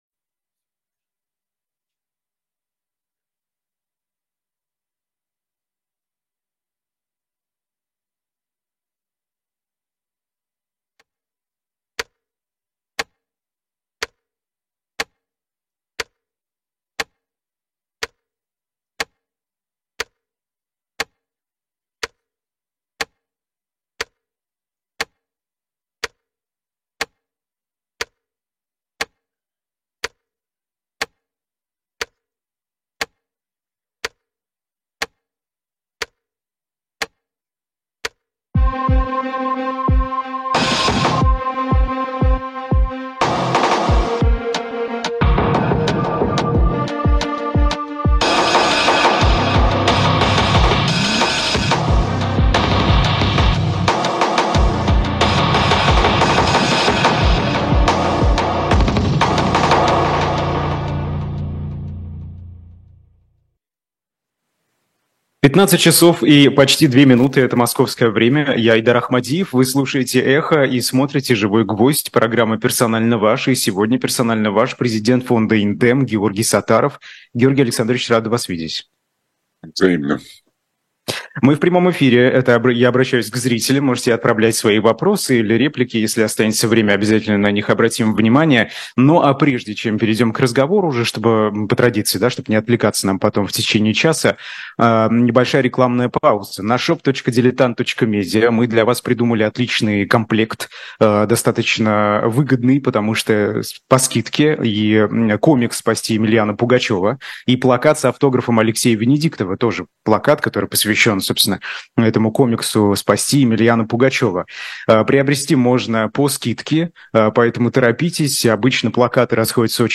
журналист
политолог